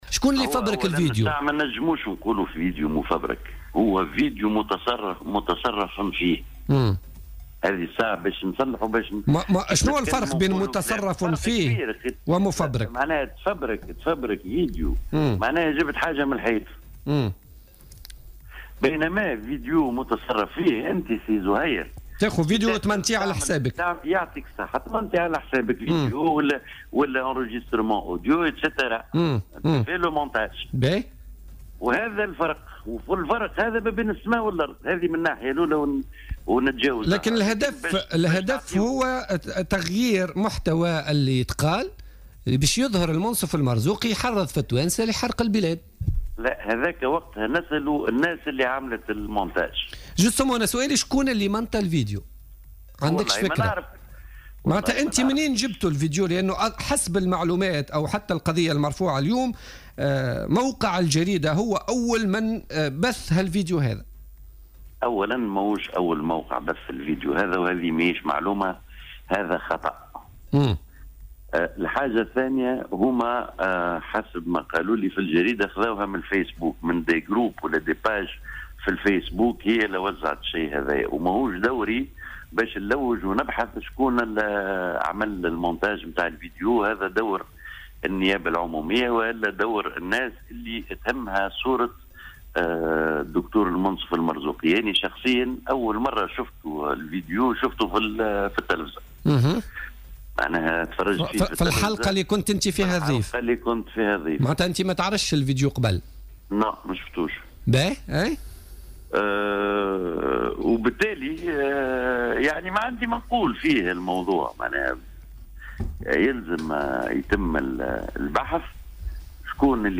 تصريح للجوهرة أف ام